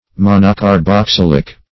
\mon`o*car*box*yl"ic\, a. [Mono- + carbonic.]